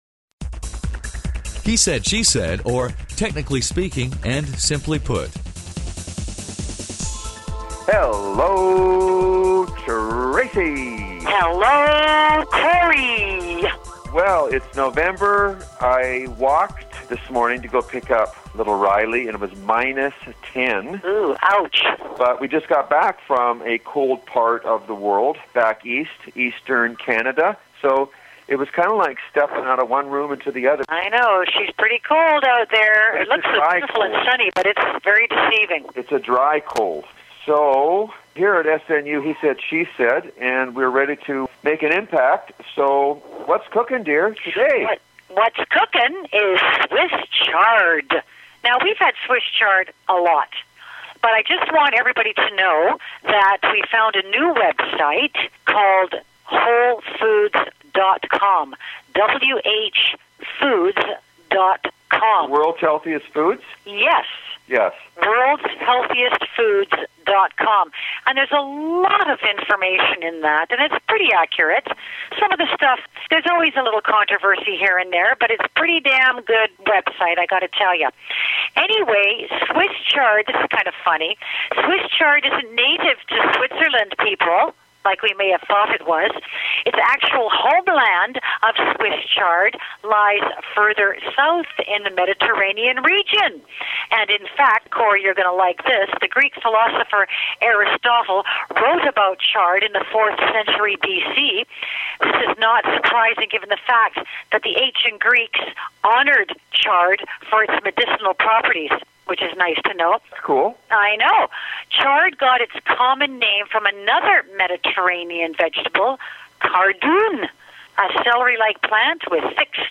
a spontaneous and humorous dialog